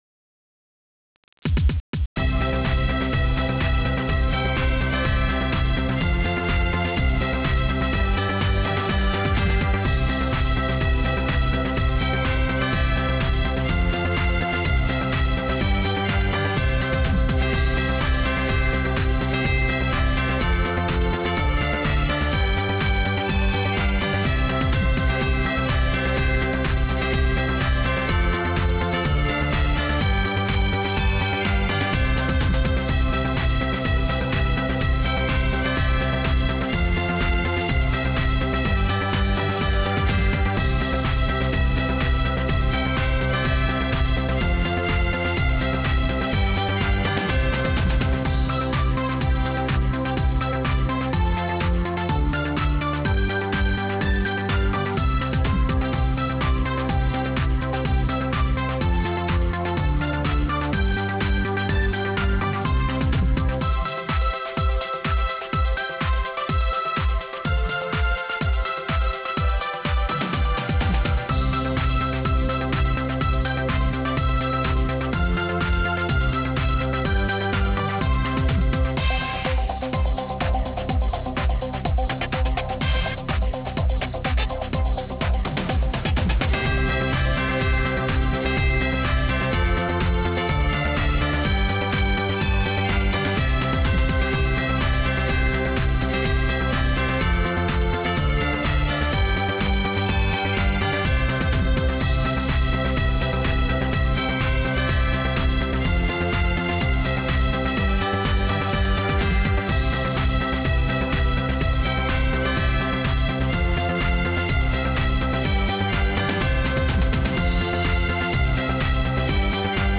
jingle bells dance remix.wav